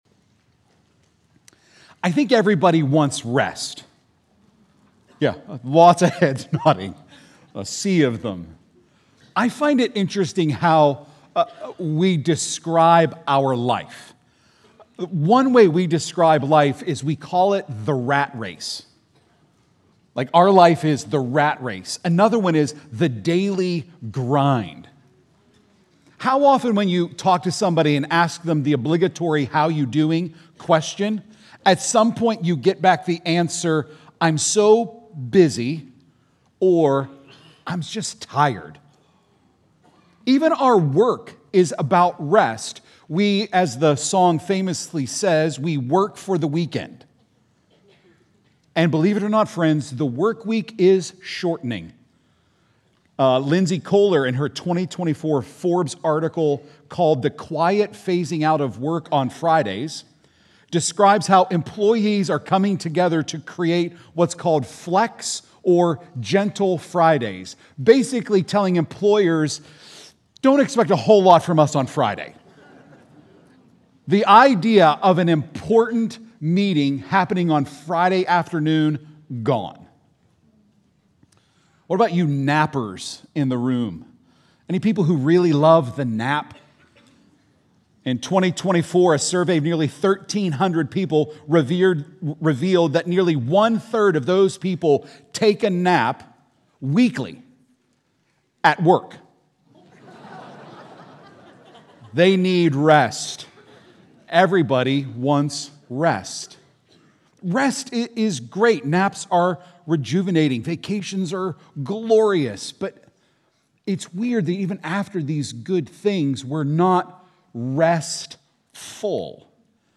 Prev Previous Sermon Next Sermon Next Title Who Is God’s Servant?